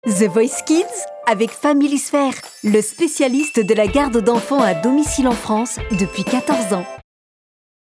Souriante
Une comédienne voix off professionnelle pour vos spots publicitaires